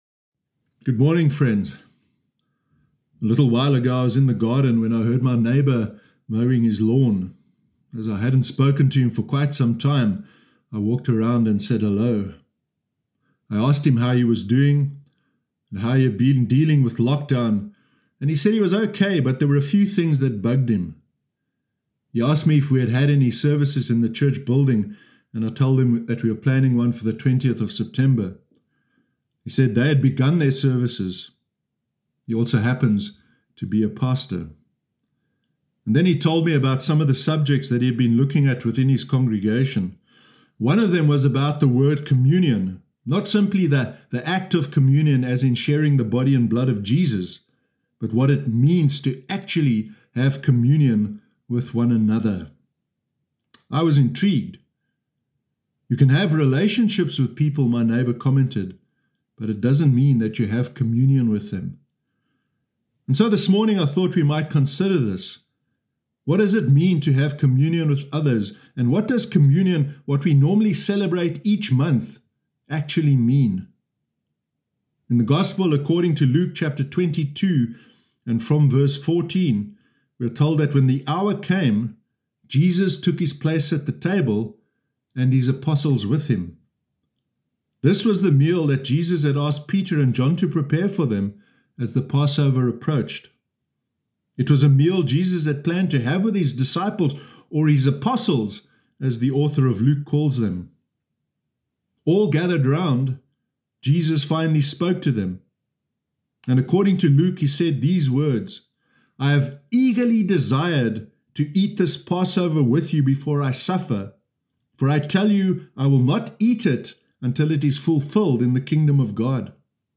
Sermon Sunday 13 September 2020